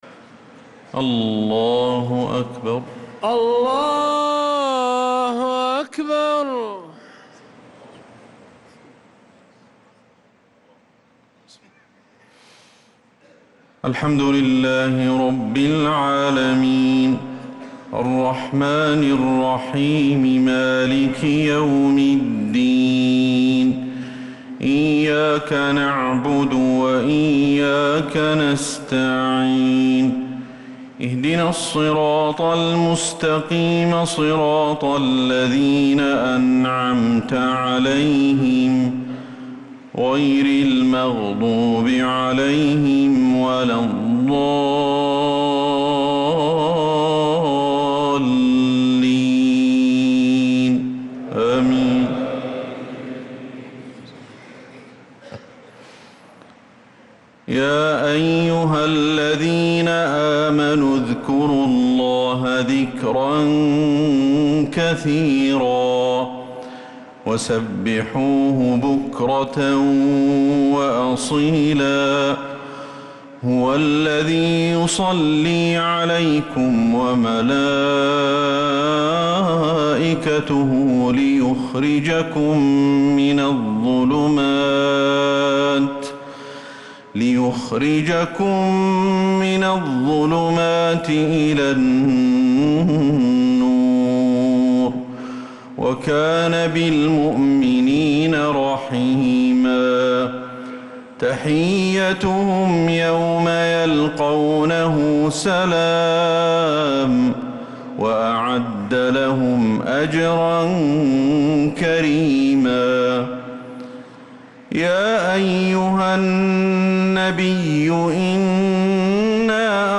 صلاة العشاء
تِلَاوَات الْحَرَمَيْن .